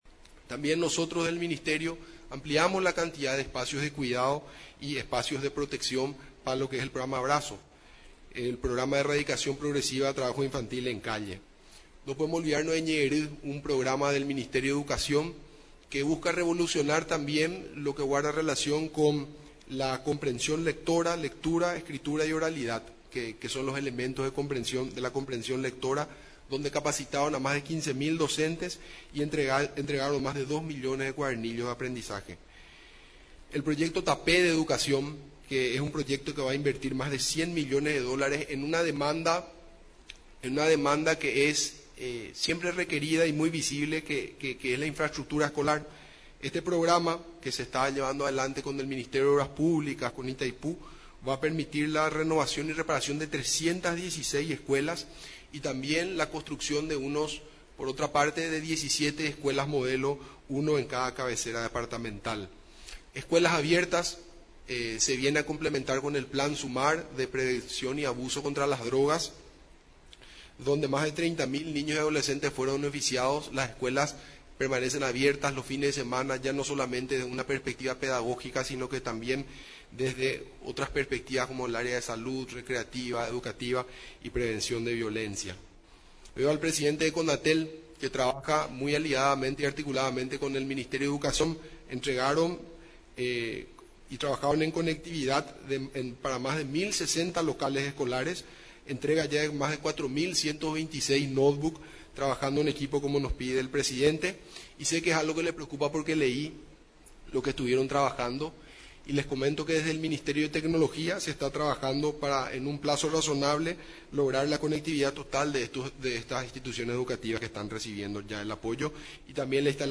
En el informe de Gestión, realizado en la Residencia Presidencial de Mburivichá Róga, estuvo presente en representación del Ejecutivo, la Primera Dama de la Nación, Leticia Ocampos, además de otras autoridades nacionales.